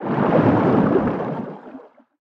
Sfx_creature_arcticray_swim_fast_04.ogg